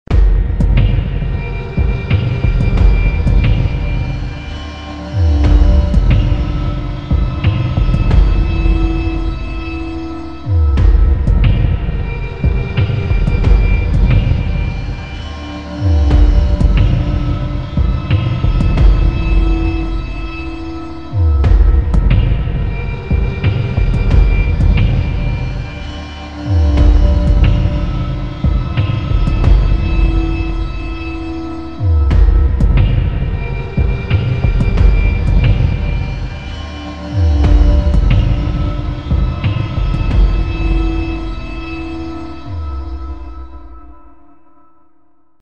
• Качество: 320, Stereo
ритмичные
громкие
Electronic
без слов
пугающие
тревожные
эпичные